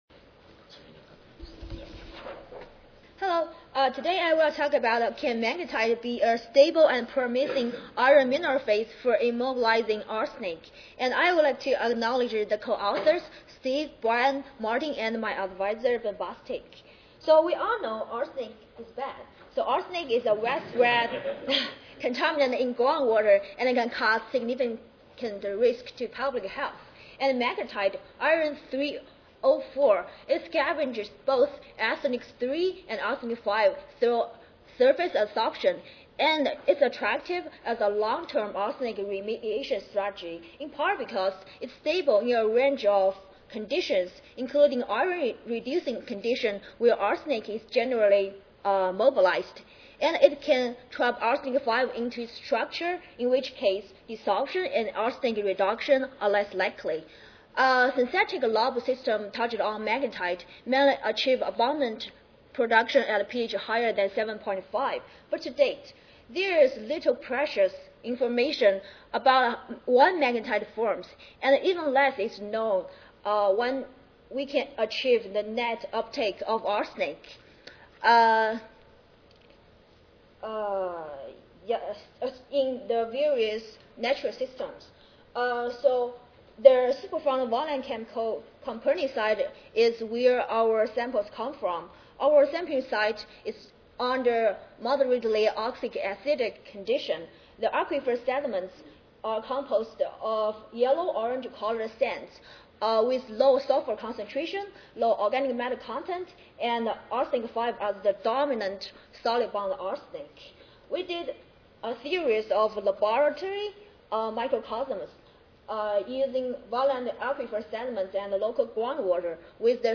Lamont-Doherty Earth Observatory Recorded Presentation Audio File